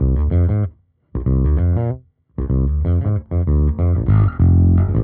Index of /musicradar/dusty-funk-samples/Bass/95bpm
DF_JaBass_95-F.wav